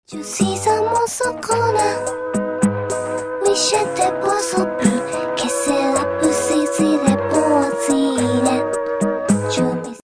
Short Sad Version